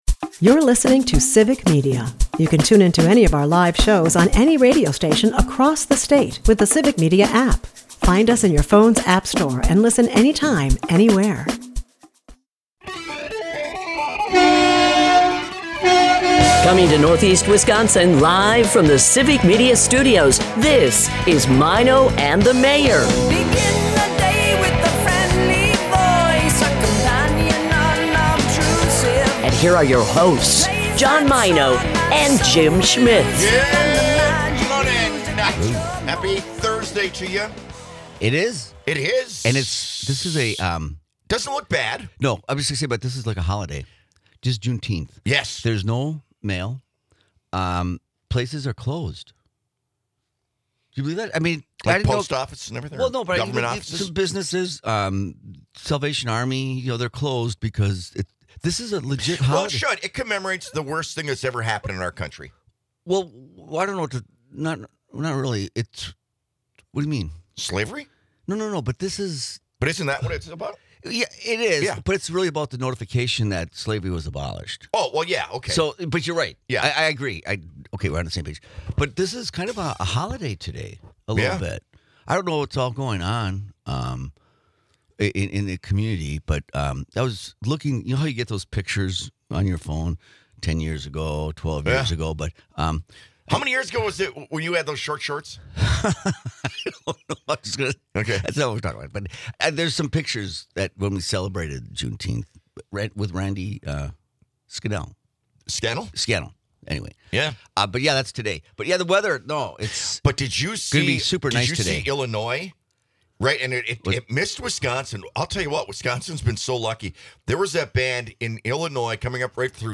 The duo humorously debates the effects of salt and sugar on dreams.